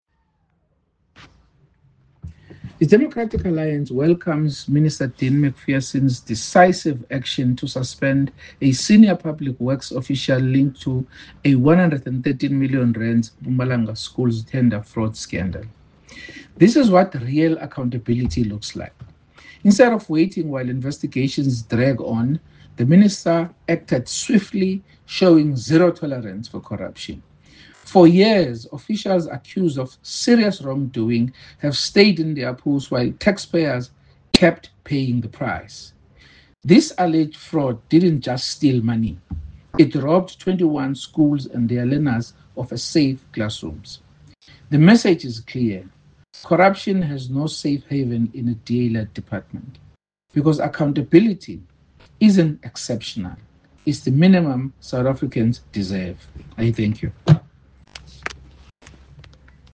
English by Bonginkosi Madikizela MP, DA Spokesperson on Public Works and Infrastructure.